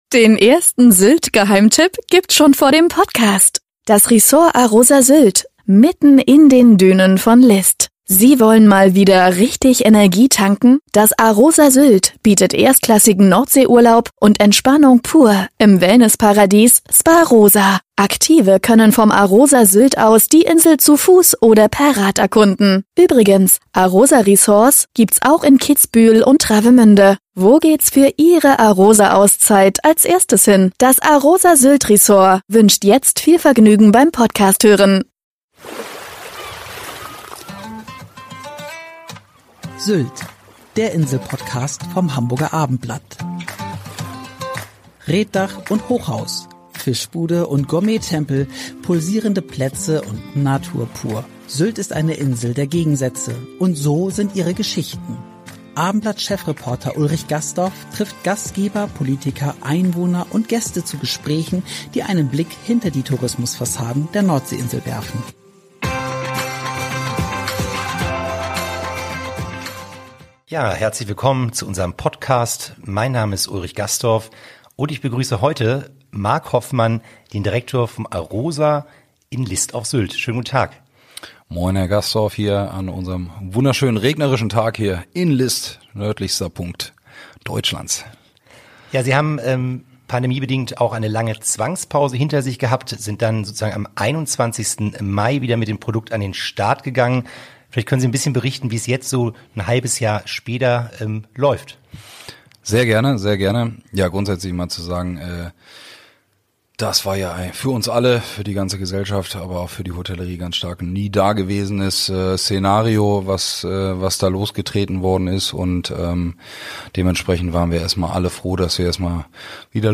Ein Gespräch